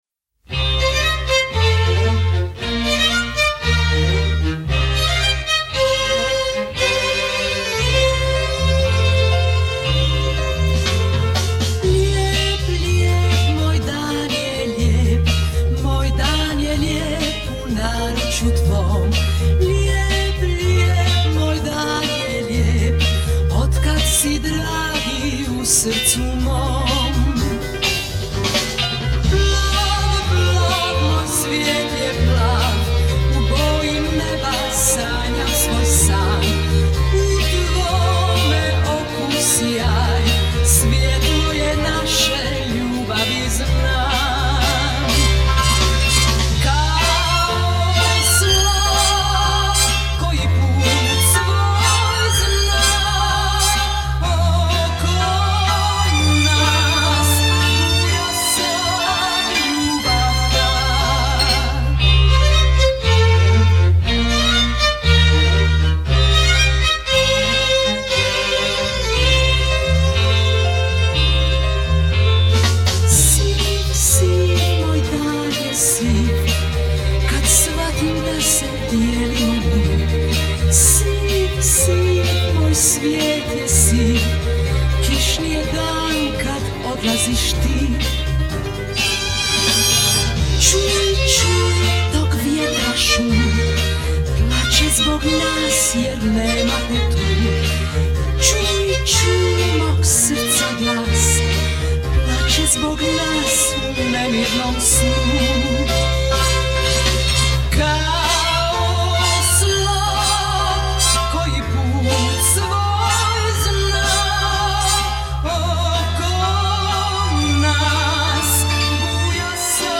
реставр.